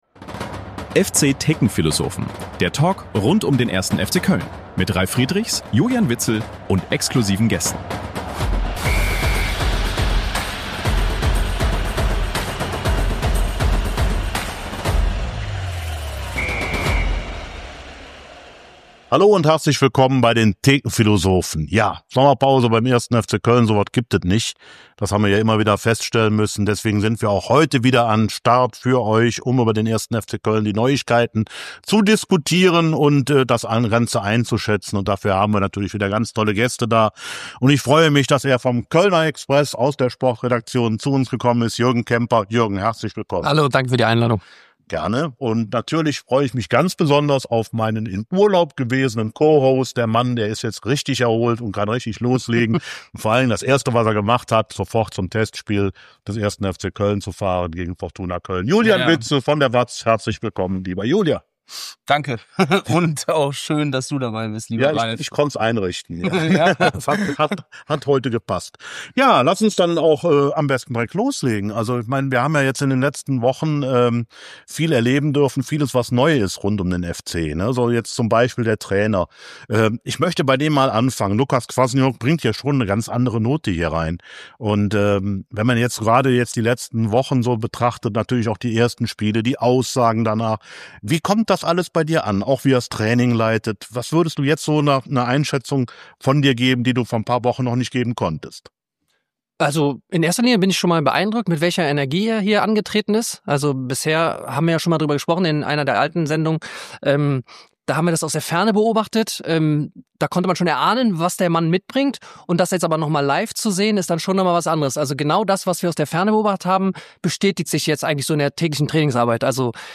• Die nächste Mitgliederversammlung: Gleich drei Vorstandsteams wollen antreten Ein süffiger Talk ohne Pause – vollgepackt mit Insider-Einschätzungen, Diskussionen und Hintergründen rund um die Kaderplanung des 1. FC Köln.